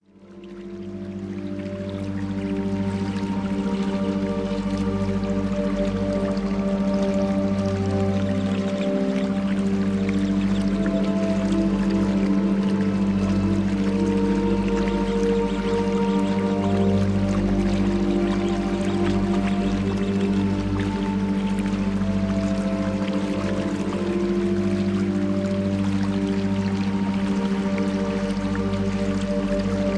Types of Entrainment Sounds: Isochronic Beat
Requirement: You must hear this audio with stereo headphones
Frequency Level: Lower Beta, Alpha and Theta